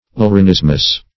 Laryngismus \Lar`yn*gis"mus\, n. [NL., fr. Gr. laryggismo`s a